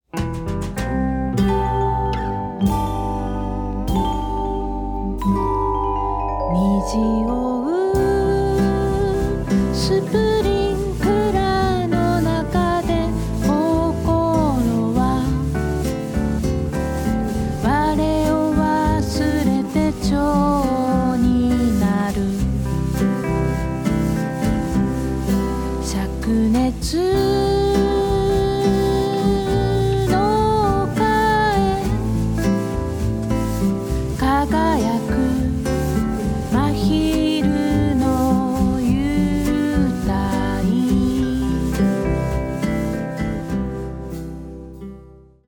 震える子猫を抱くような歌声。
スティール・ギターやサックス、リコーダーにヴィブラフォンも加わった７人体制のバンドサウンドはほんのりポップな装い。